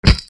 ビーチボールが身体に当たる 03